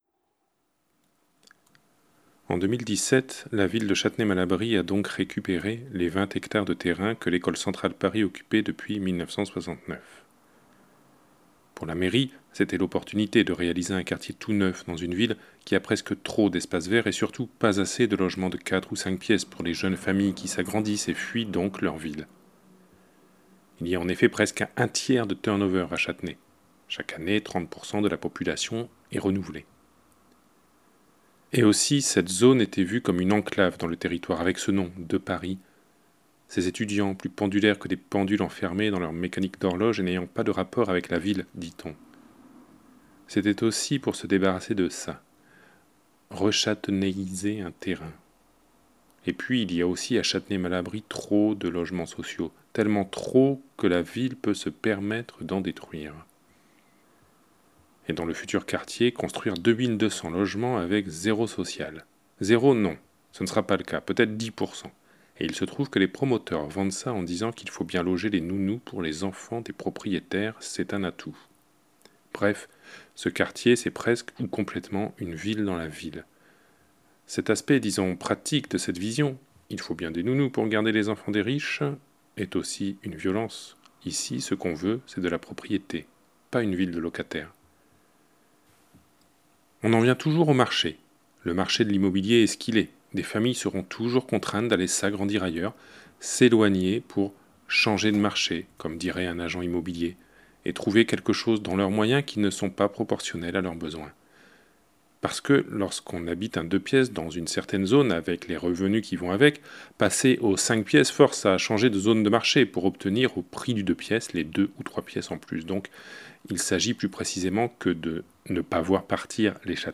Extrait de Lisières limites, lu par